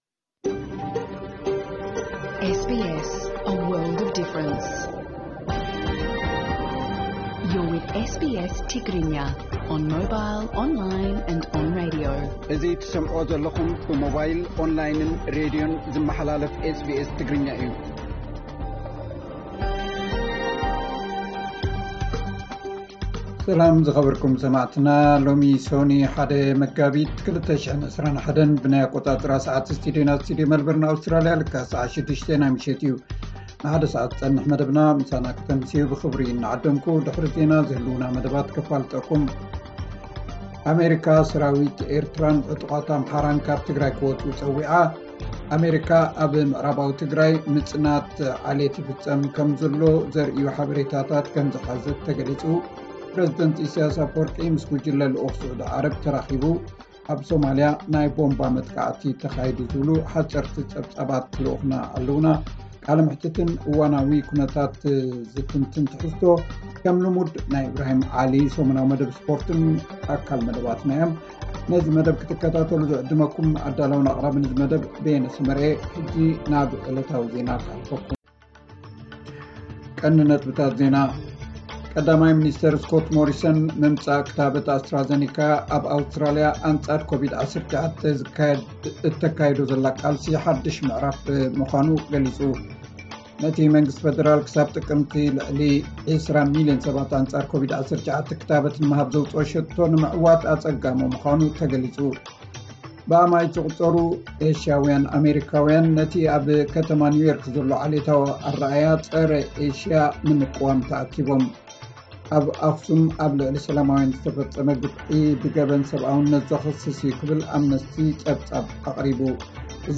ዕለታዊ ዜና SBS ትግርኛ 01 መጋቢት 2021